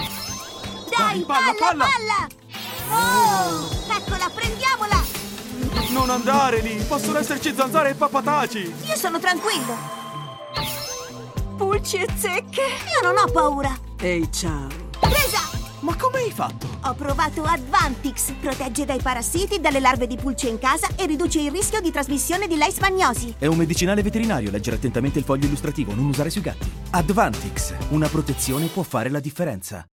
Allegro/Frizzante - Interpretato